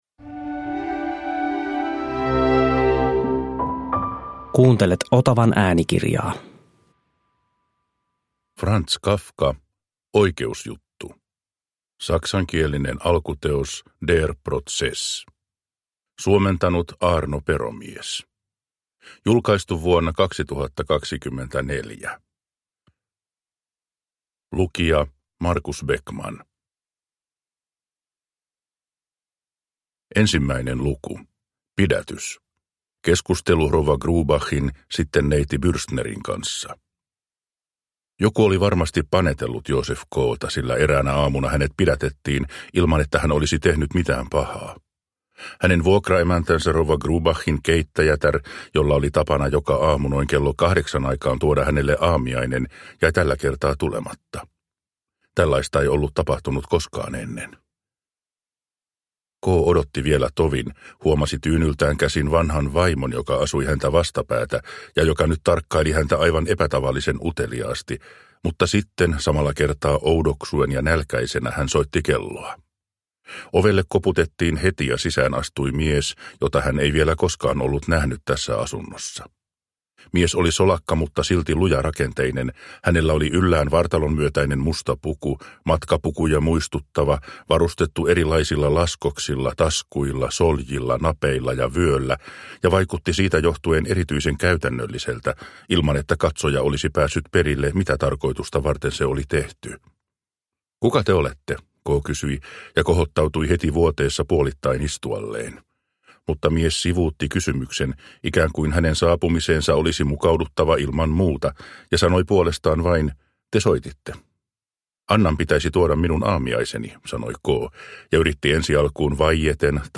Oikeusjuttu – Ljudbok